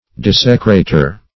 Desecrater \Des"e*cra`ter\, n.